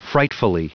Prononciation du mot frightfully en anglais (fichier audio)
Prononciation du mot : frightfully